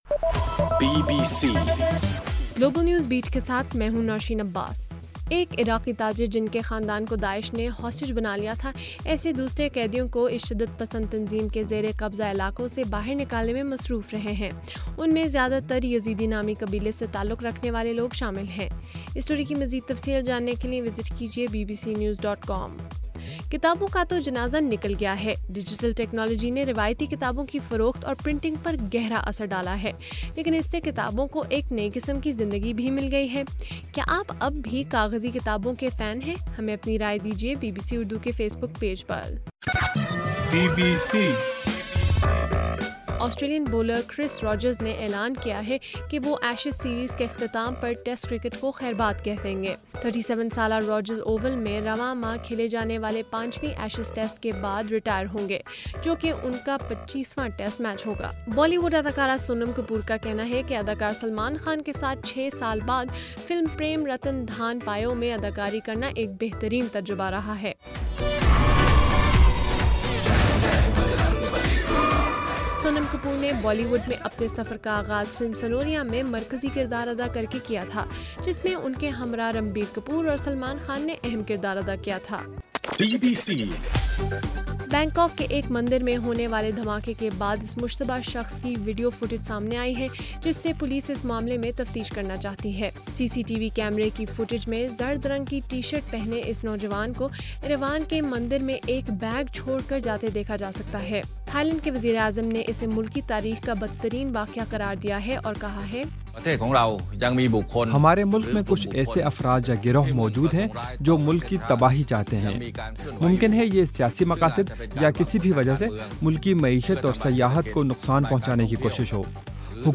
اگست 19: صبح 1 بجے کا گلوبل نیوز بیٹ بُلیٹن